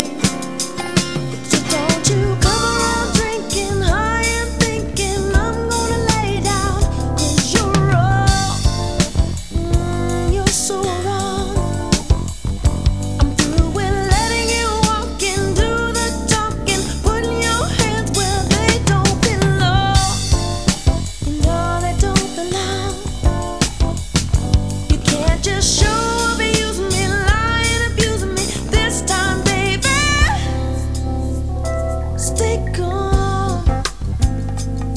Ultra-cool Acid Jazz - Definitely my best buy of the year!